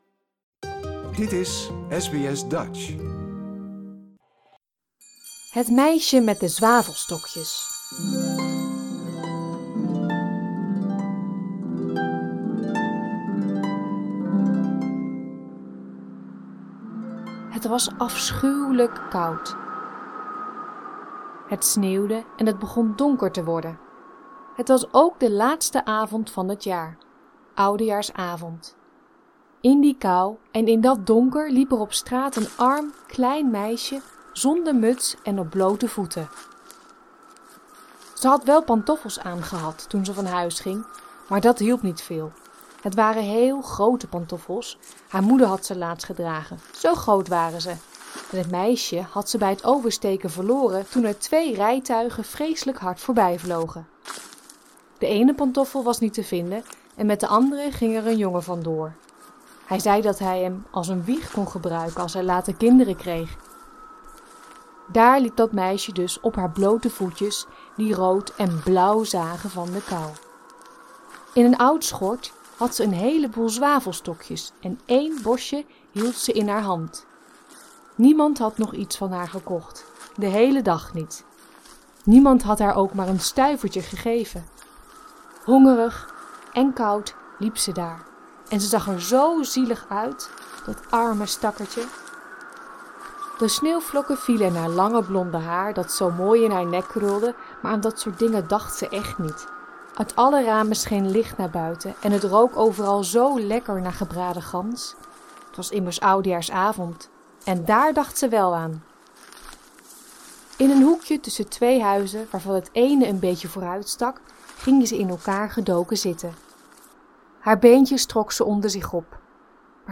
Het Meisje met de Zwavelstokjes, een sprookje...